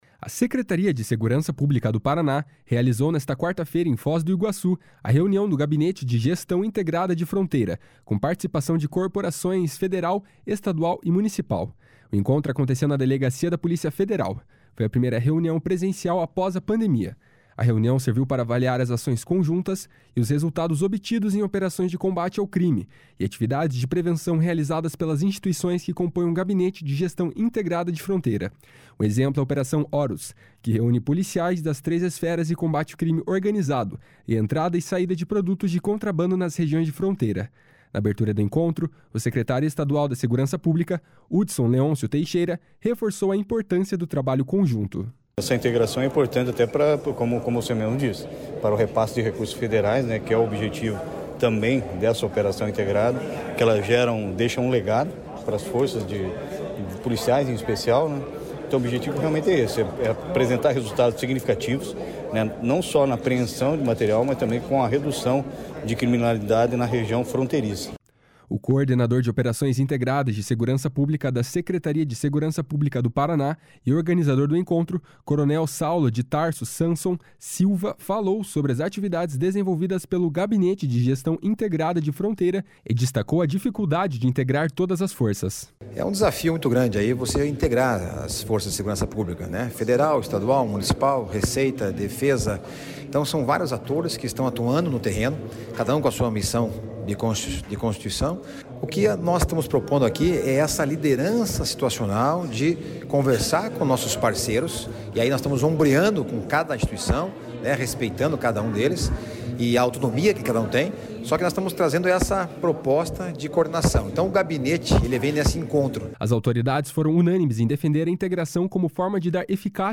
Na abertura do encontro, o secretário estadual da Segurança Pública, Hudson Leôncio Teixeira, reforçou a importância do trabalho conjunto. // SONORA HUDSON LEONCIO //